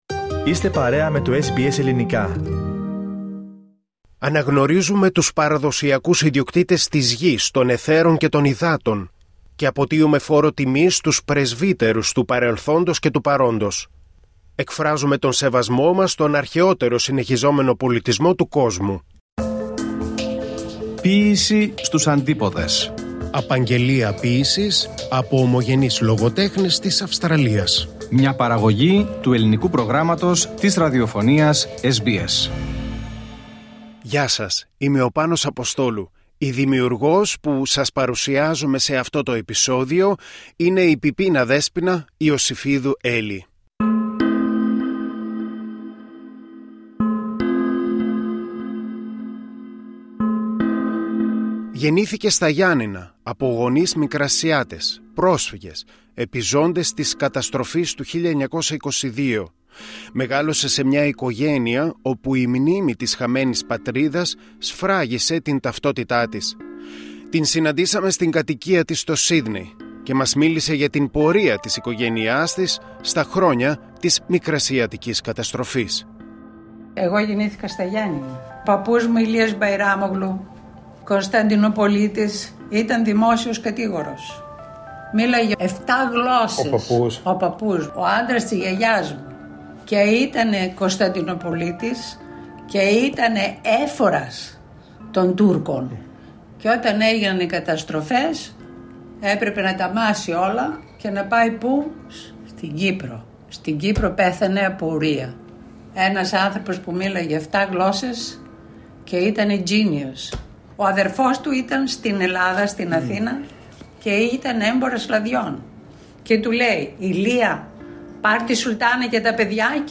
Την συναντήσαμε στην κατοικία της στο Σύδνεϋ και μας μίλησε για την πορεία της οικογένειάς της στα χρόνια της Μικρασιατικής Καταστροφής, προσωπικές ιστορίες όταν ζούσε στα Ιωάννινα και την απόφασή της να εγκαταλείψει την Ελλάδα.
η ηχογράφηση έγινε σε ραδιοθάλαμο του οργανισμού στη Μελβούρνη.